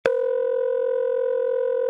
telephonerington2.mp3